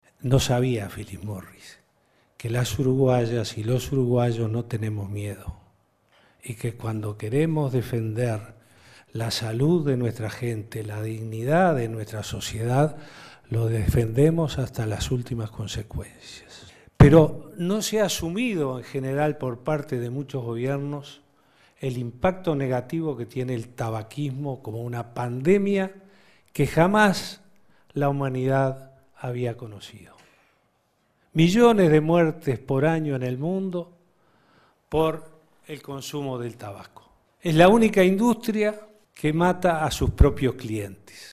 En conferencia de prensa Vázquez señaló que esta mañana estuvo reunido con parlamentarios japoneses y sostuvo que encontraron una recepción "muy favorable a una resolución rápida desde el punto de vista político";.
Escuche a Vázquez